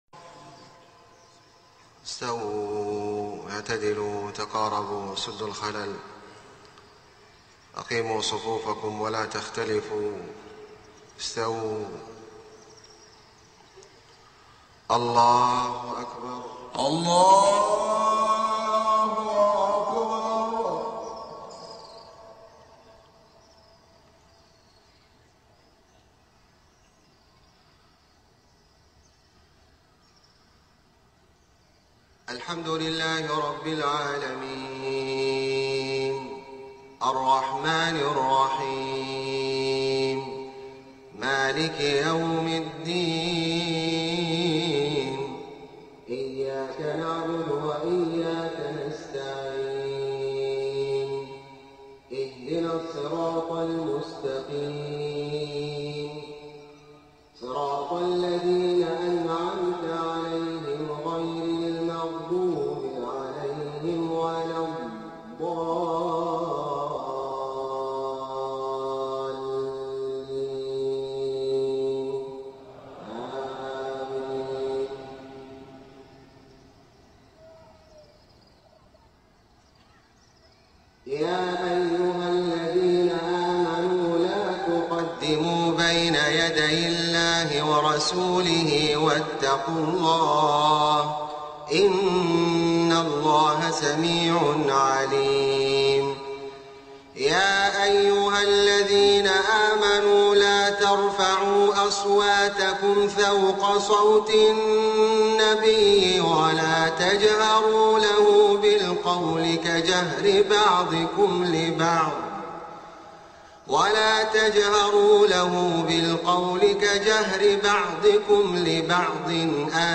صلاة الفجر 23 صفر 1429هـ سورة الحجرات > 1429 🕋 > الفروض - تلاوات الحرمين